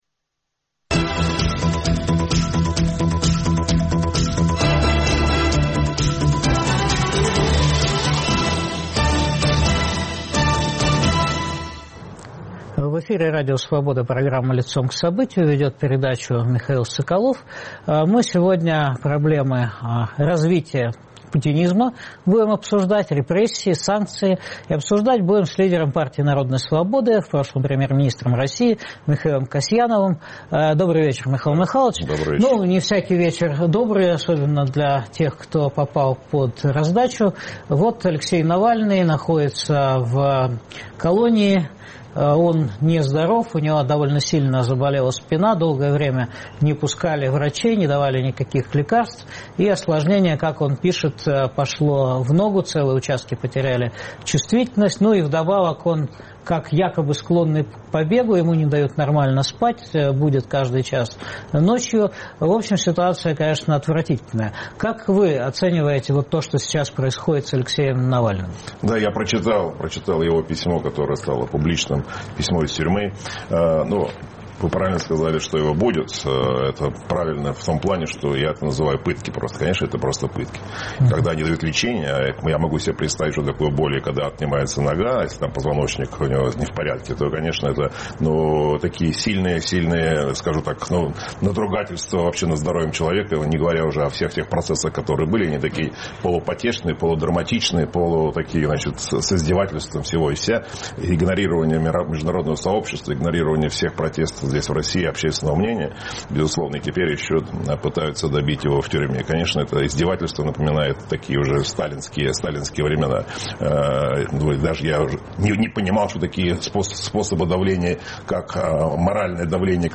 Как Кремль готовит Россию к выборам в Думу? Что происходит с экономикой? Обсуждаем с лидером Партии народной свободы Михаилом Касьяновым.